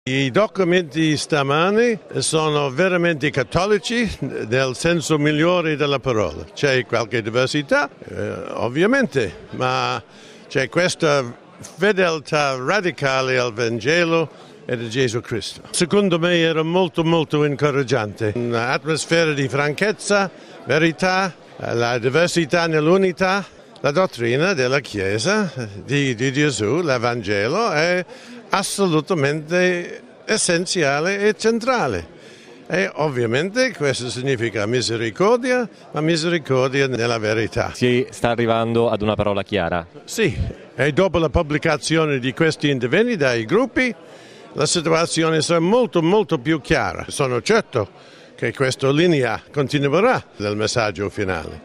il cardinale George Pell, prefetto della Segreteria per l'Economia